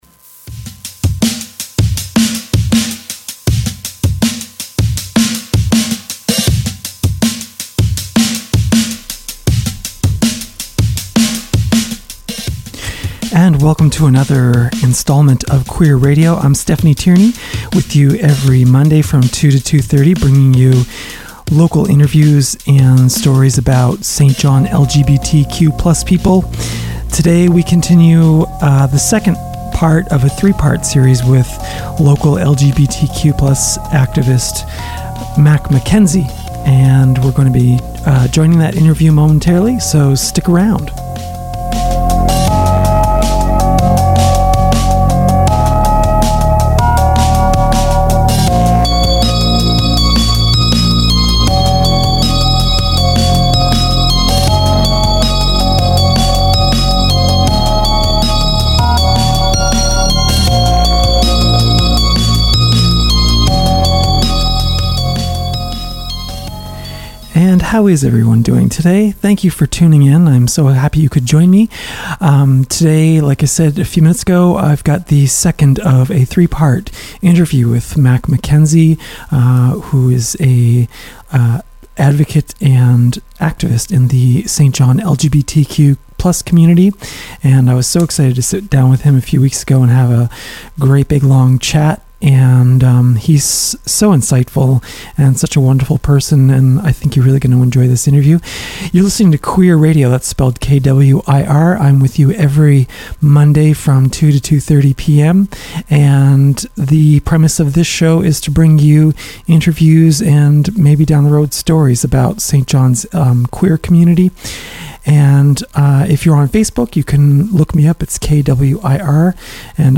three-part conversation